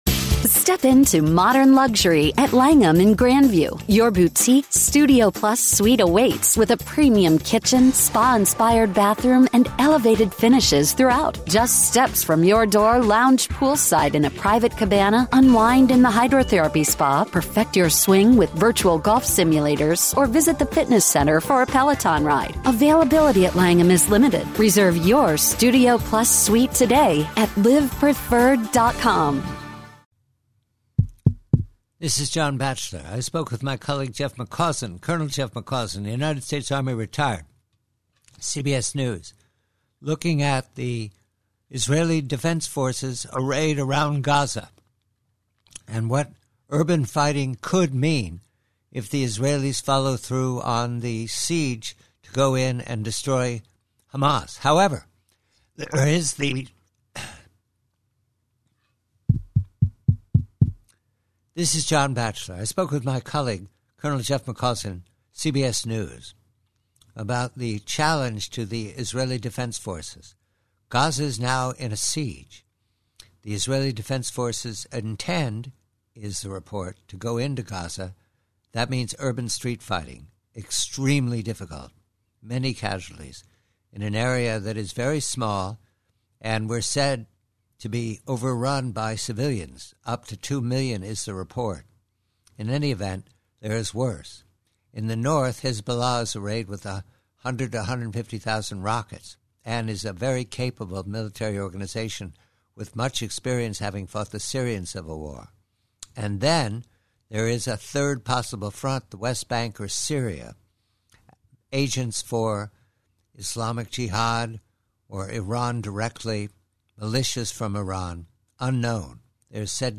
PREVIEW: From a longer conversation